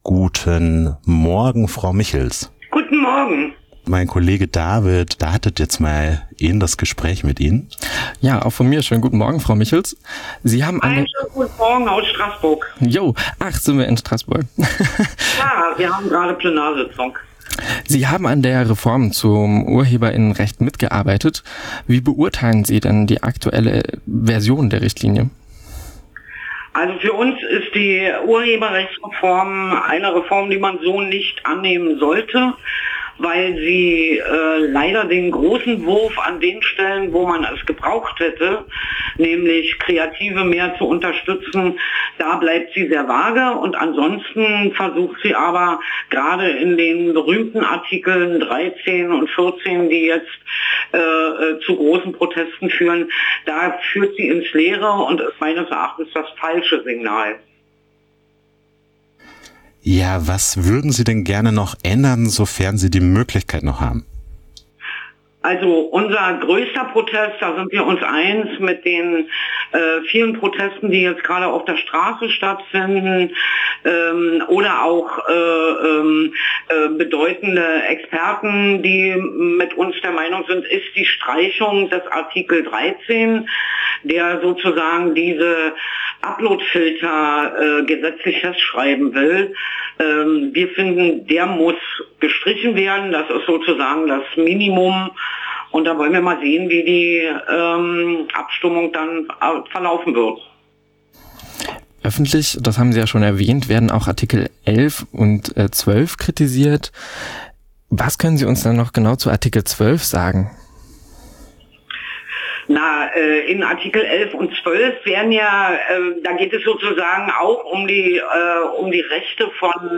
Download Interview mit Martina Michels zur geplanten Novelle der EU-Urheber*innenrechtsrichtline Mit Martina Michels, Abgeordnete von der Partei die Linke im Europ�ischen Parlament, sprachen wir live in der Sendung �ber die Reform.
Aus Strasbourg antwortete sie auf unsere Fragen zu den geplanten �nderungen, der Kritik an den Artikeln 11, 12 und 13 und wie aus ihrer Perspektive die Abgeordneten des Europ�ischen Parlaments die laufenden und geplanten Proteste wahrnehmen.
Interview Martina Michels EU-Urheber*innenrechtsreform.mp3